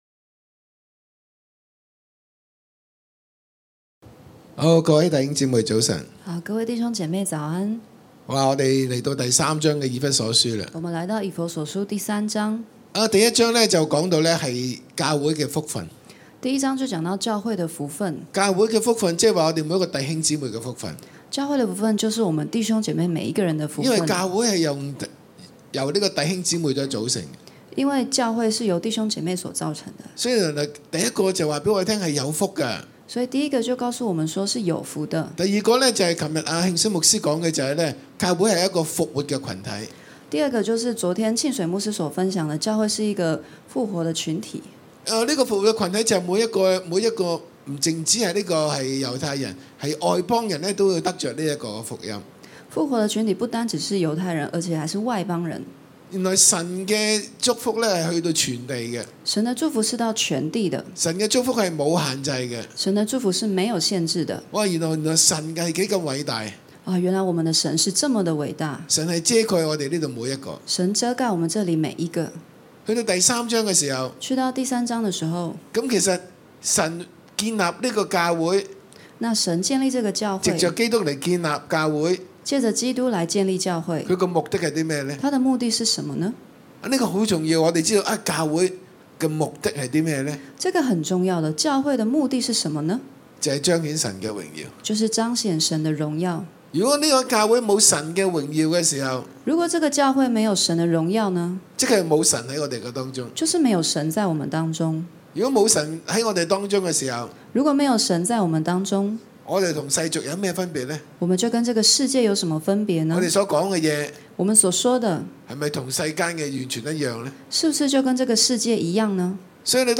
v21經文顯示耶穌基督是藉著信徒得著榮耀。現場會眾一一到台前，分享生命經歷被耶穌的愛觸摸，被主拯救和幫助，心思意念被翻轉改變，有力量起來服事神，超過所想所求，頌讚主耶穌的恩典和榮耀！